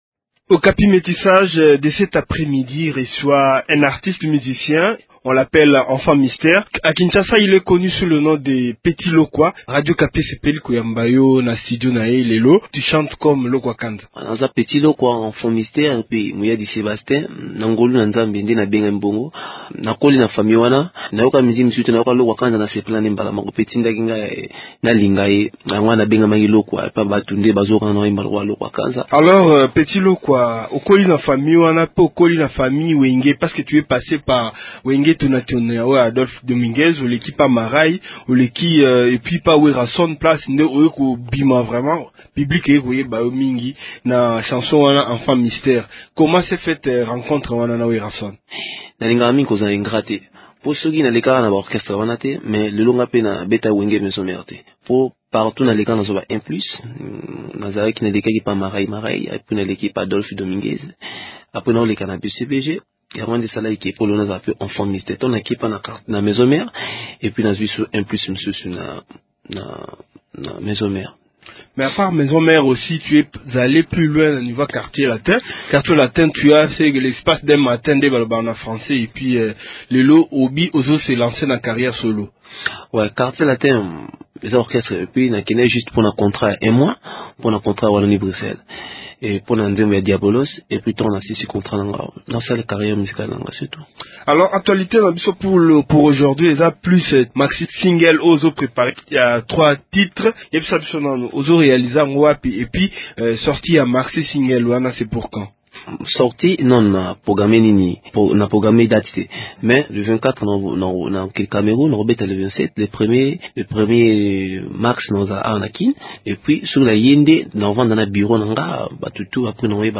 L’artiste s’entretient